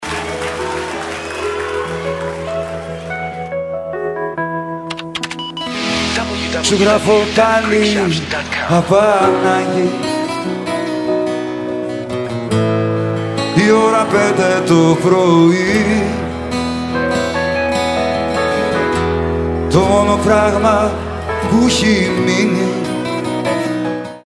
CD 2 (Live in New York):